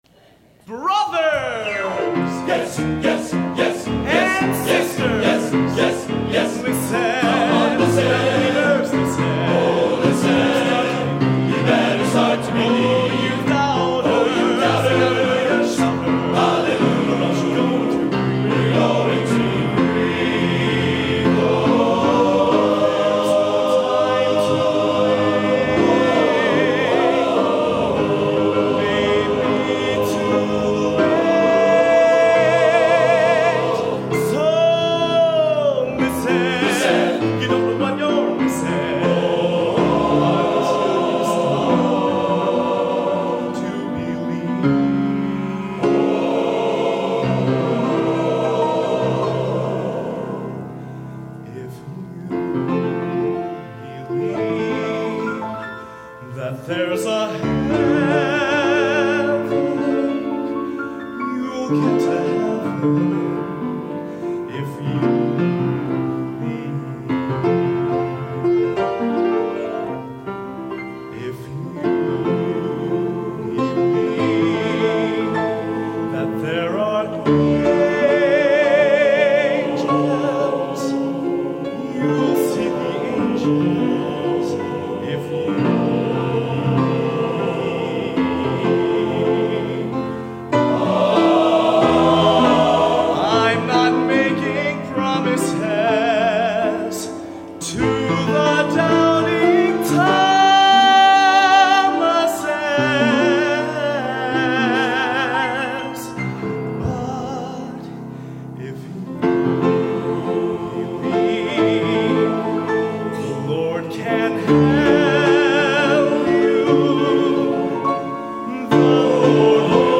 Genre: Broadway | Type: Solo